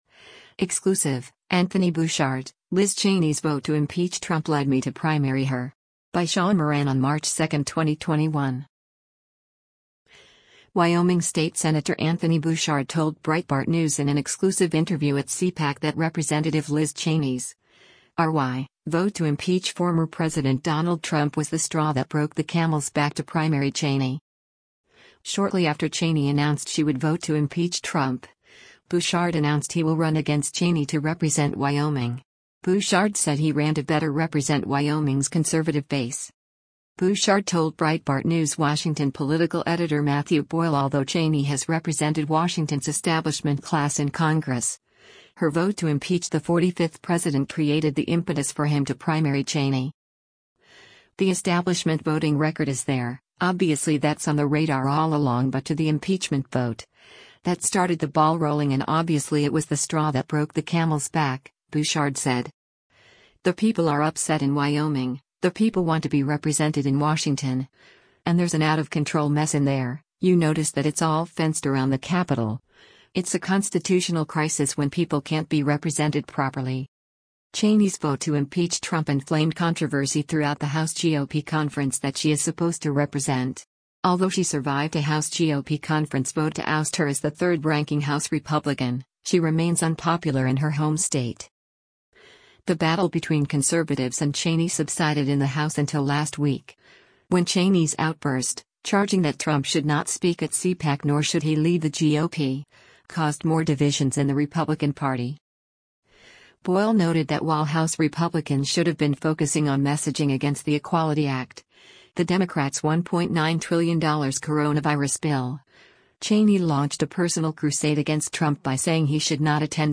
Wyoming state Sen. Anthony Bouchard told Breitbart News in an exclusive interview at CPAC that Rep. Liz Cheney’s (R-WY) vote to impeach former President Donald Trump was the “straw that broke the camel’s back” to primary Cheney.